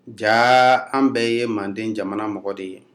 This is a dialogue of people speaking Nko as their primary language.